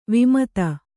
♪ vimata